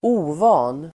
Uttal: [²'o:va:n]